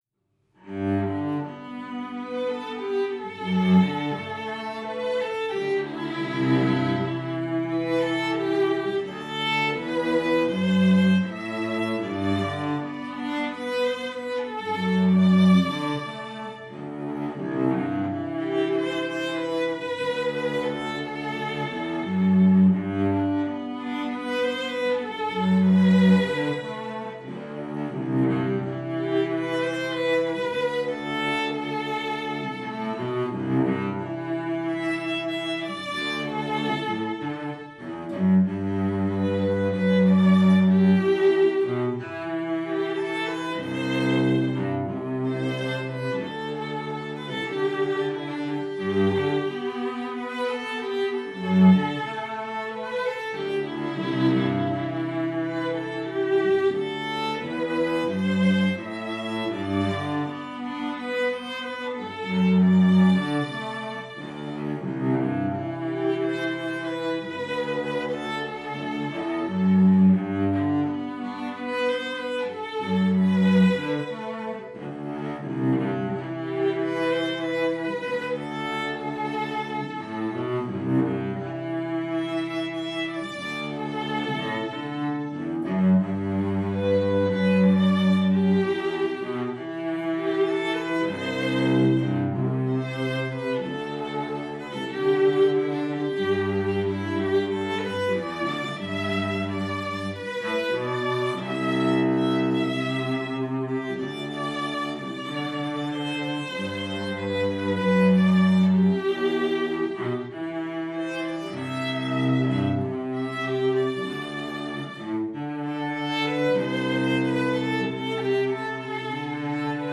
Duo: Violin & Cello – Contemporary